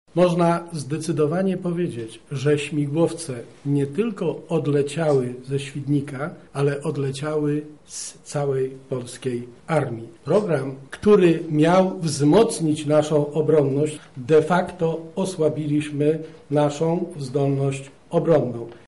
-mówi Stanisław Żmijan, poseł Platformy Obywatelskiej.